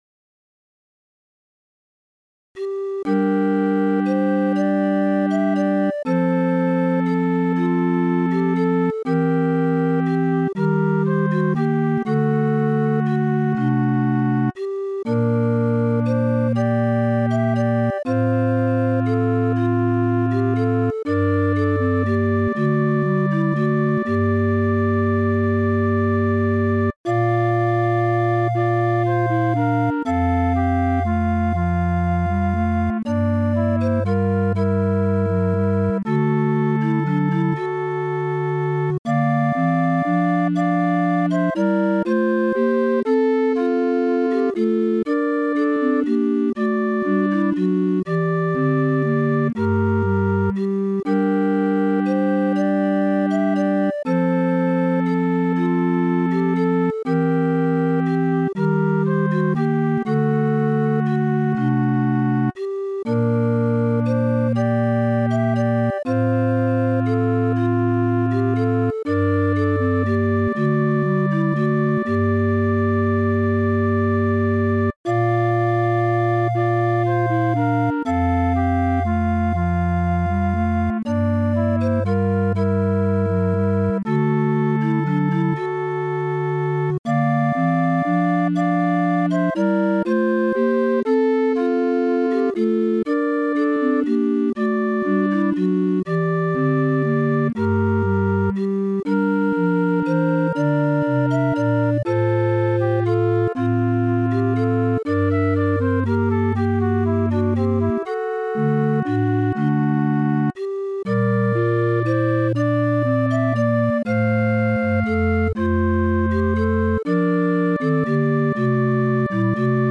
Profano ; Canción de amor Carácter de la pieza : romántico
SATB (4 voces Coro mixto )
Tonalidad : sol menor (centro tonal)